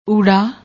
noun, masculinefirst letter of Gurmukhi script used to form vowel /u/ as in pull, /u/ as in pool and /o/ as in pole